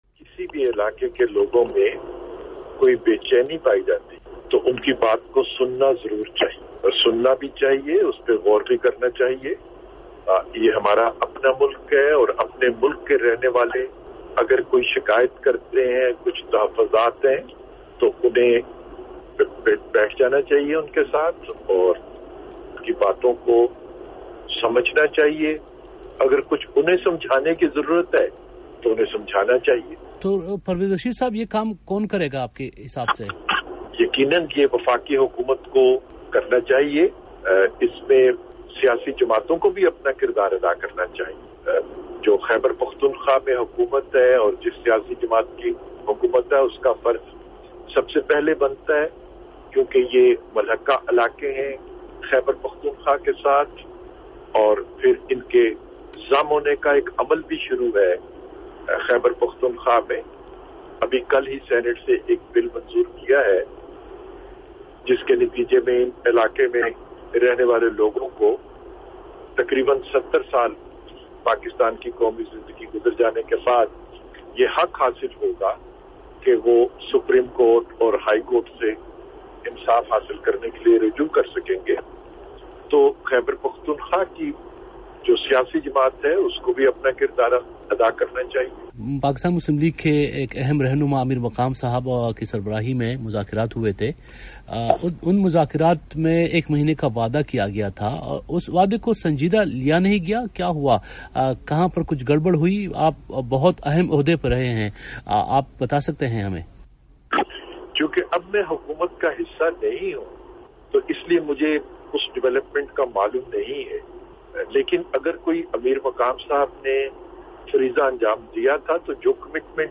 د واکمنه مسلم لیگ گوند مهم مشر او د هېواد د اطلاعاتو پخواني وزير ښاغلي پرویز رشید وي او ډیوه ته په یوه مرکه کې وویل: